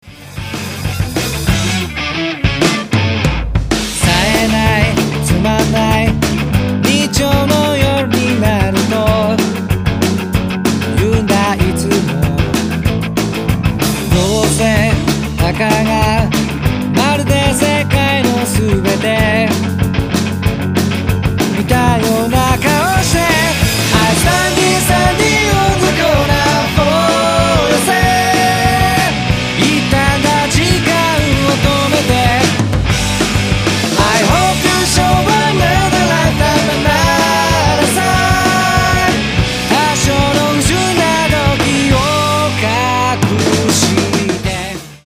どこまでもエヴァー・グリーンなパワー・ポップ！！！！